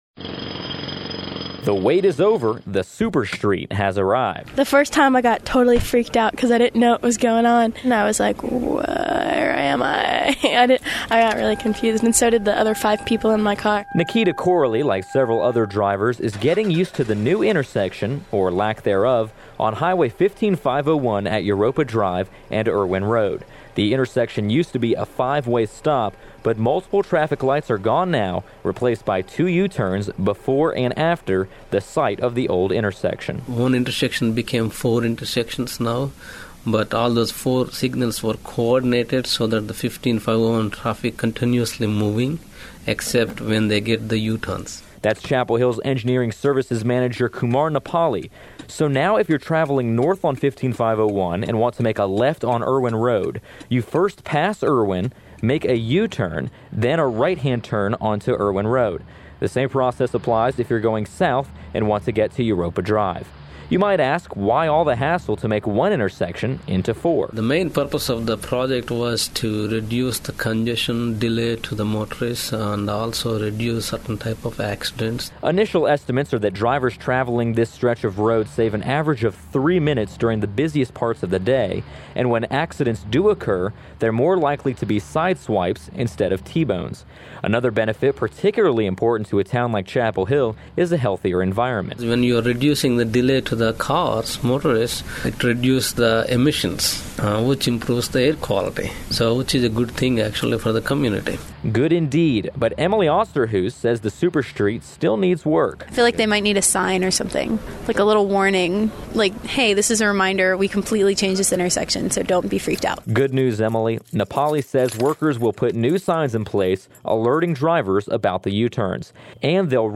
First Place Radio II – News